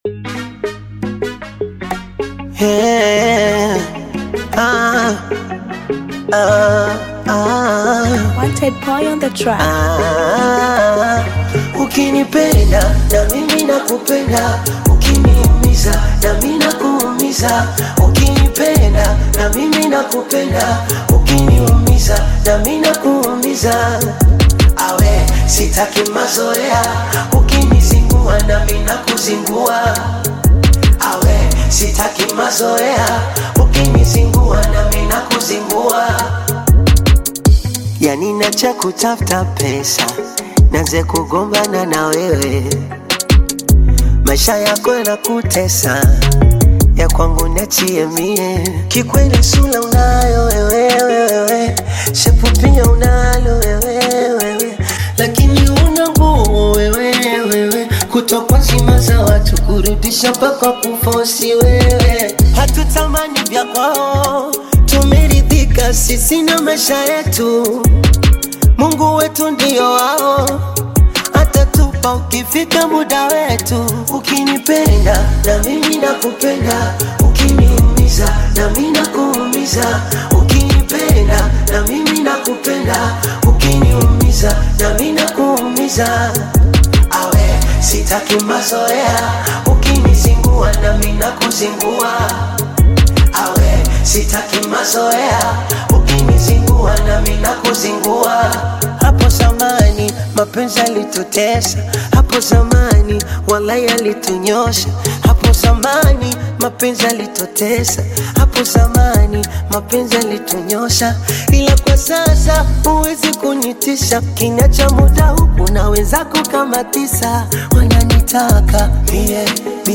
Tanzanian Bongo Flava artist, singer and songwriter
African Music